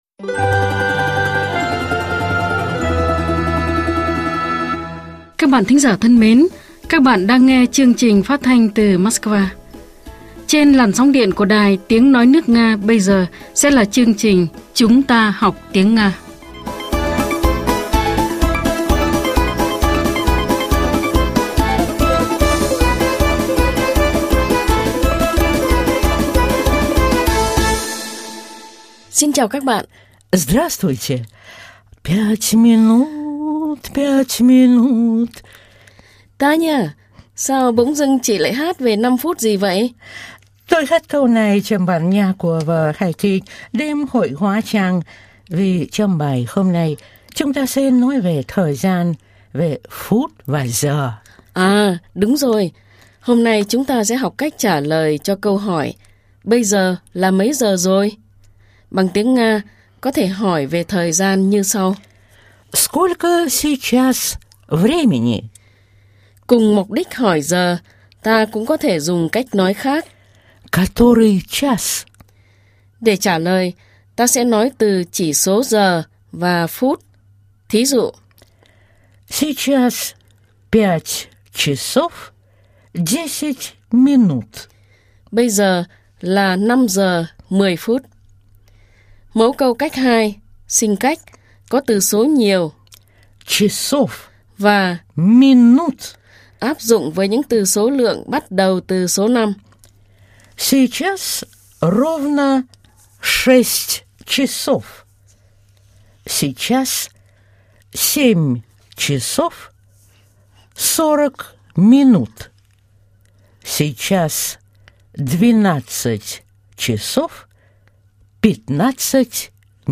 Bài 31 – Bài giảng tiếng Nga
Nguồn: Chuyên mục “Chúng ta học tiếng Nga” đài phát thanh  Sputnik